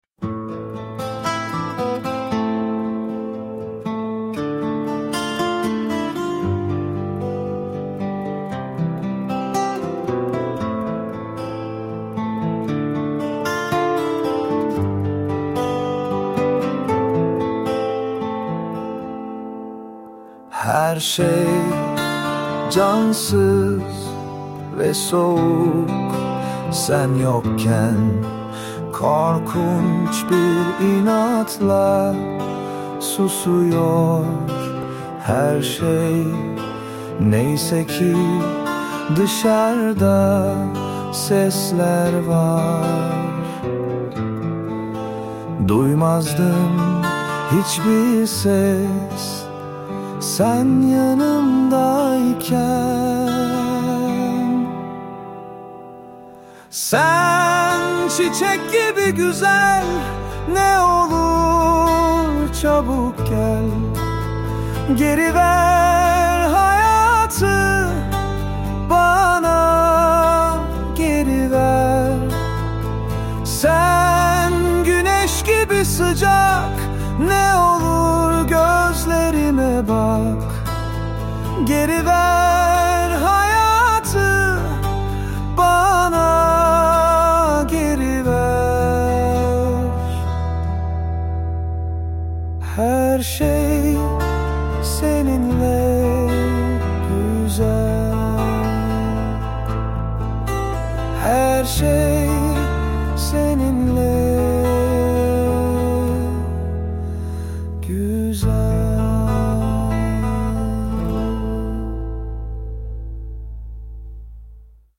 Tür : Akdeniz, Pop, Slow Rock